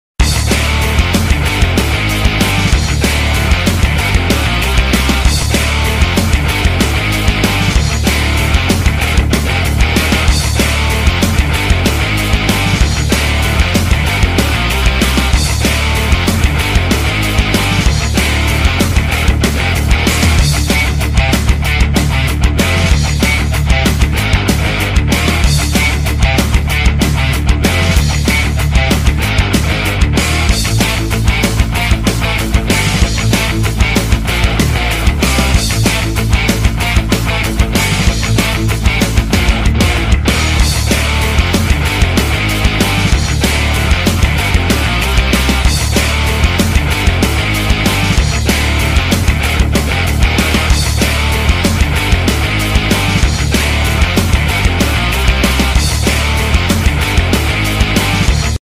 Nhạc Chuông Nhạc Phim